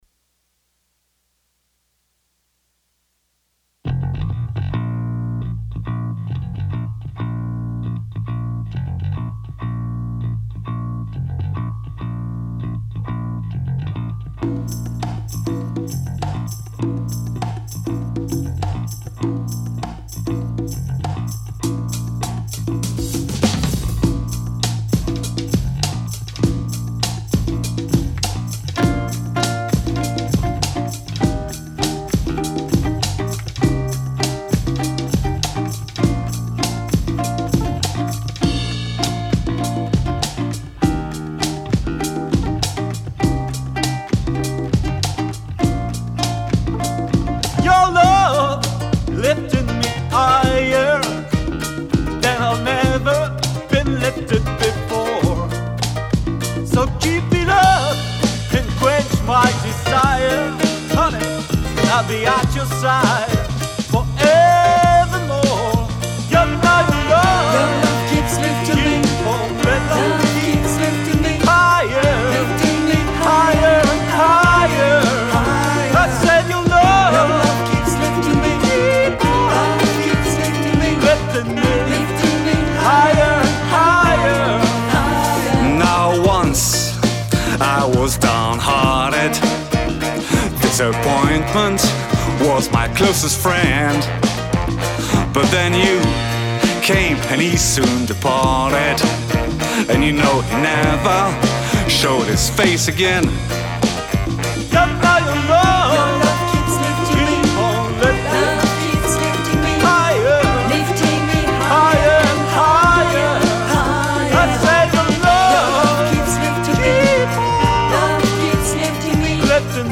# ukuleleCover
# duo
ukulélé rythmique & chant
chant lead
choeurs
basse
Sax alto + trombone
trompette et sax baryton